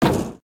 sounds / material / human / step / tin4.ogg